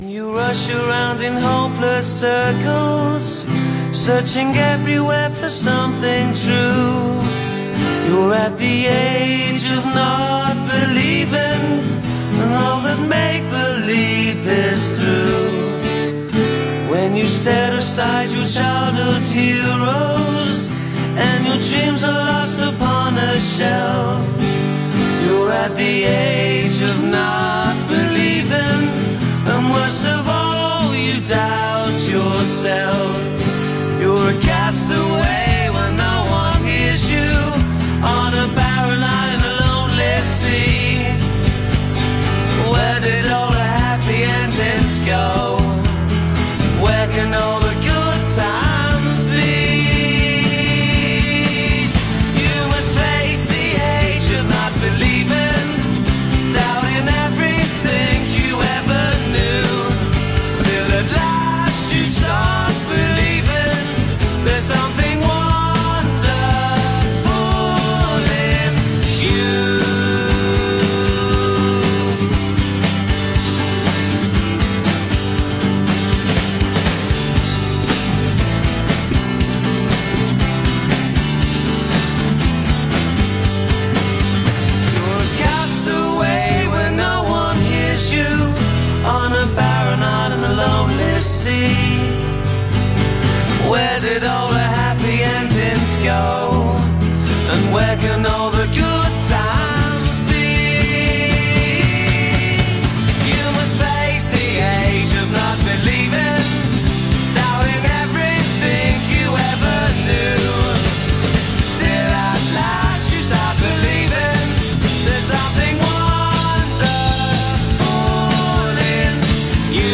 Brit-Pop